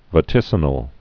(və-tĭsə-nəl)